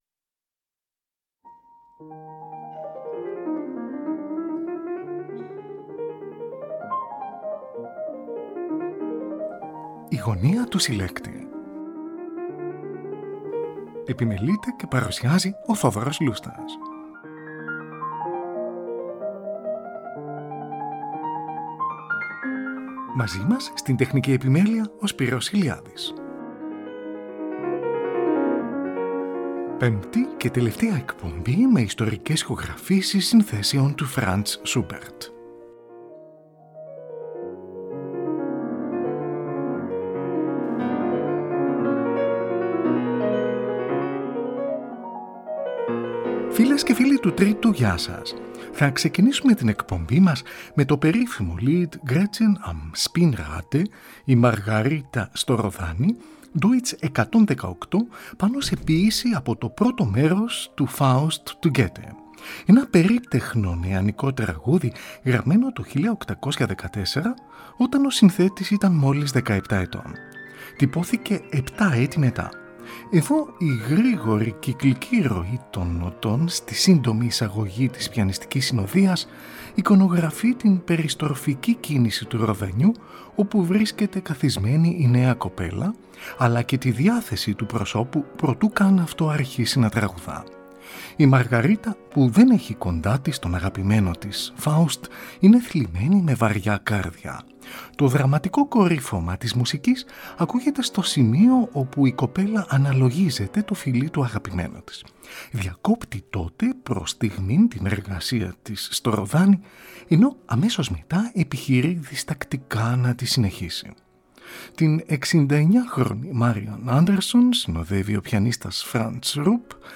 ΙΣΤΟΡΙΚΕΣ ΗΧΟΓΡΑΦΗΣΕΙΣ ΣΥΝΘΕΣΕΩΝ ΤΟΥ FRANZ SCHUBERT (ΠΕΜΠΤΗ ΚΑΙ ΤΕΛΕΥΤΑΙΑ ΕΚΠΟΜΠΗ)
Την υψίφωνο Tiana Lemnitz συνοδεύει ο Michael Raucheisen, από ηχογράφηση της Ραδιοφωνίας του Βερολίνου, στις 10 Ιουνίου 1944, κατά τη διάρκεια του Δευτέρου Παγκοσμίου Πολέμου.